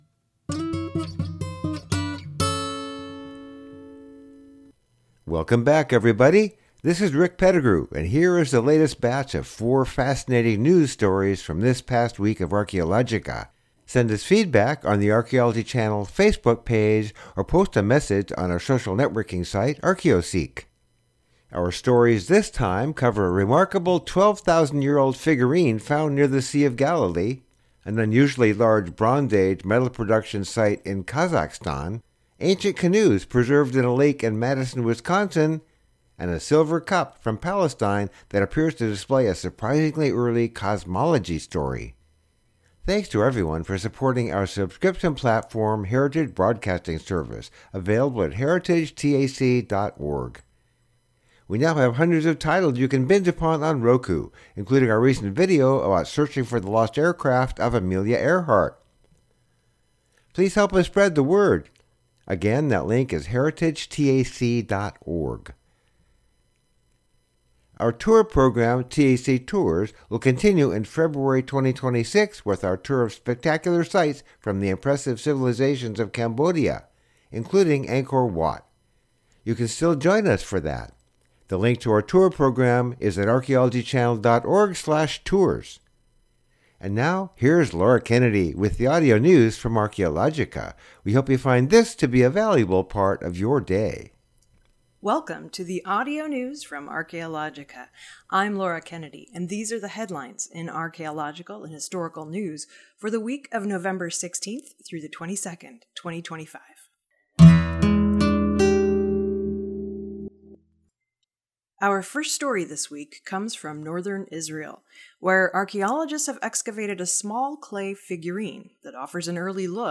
Audio News for November 16th through the 22nd, 2025